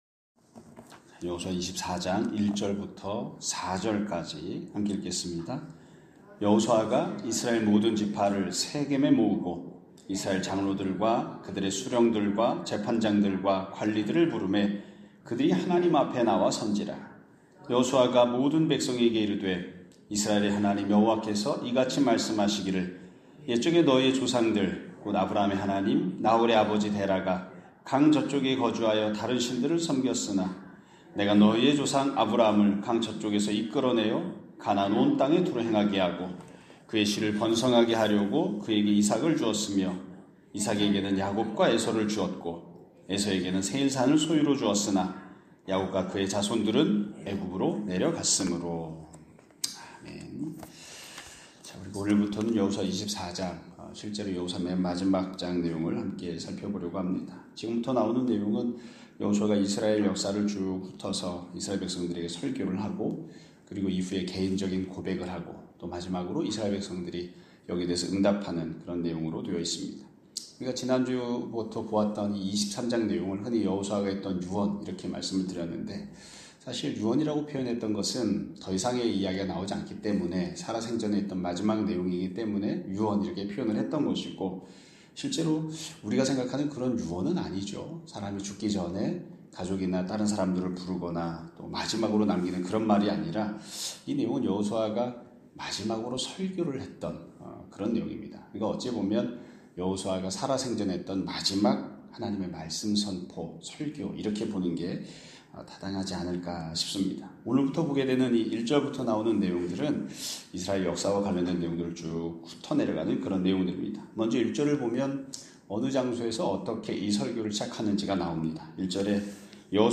2025년 2월 21일(금요일) <아침예배> 설교입니다.